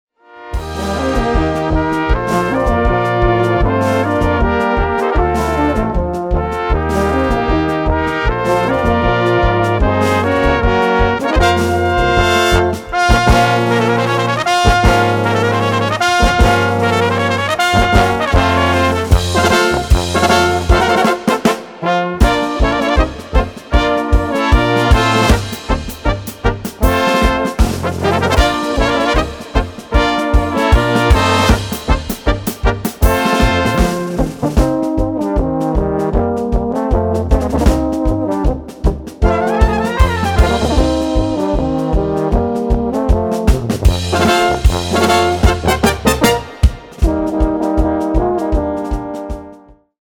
Gattung: Kleine Besetzung
Besetzung: Kleine Blasmusik-Besetzung
1. Trompete in B
Tenorhorn in B / C / F
Bariton in B / C / F
Tuba in C / B
Schlagzeug